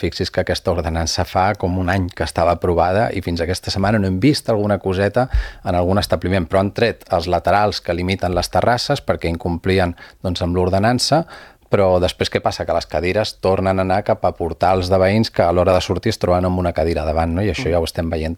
A l’espai de l’entrevista política, Enric Gómez, ha recordat que es va aprovar fa un any però que s’han començat a veure accions aquests últims dies.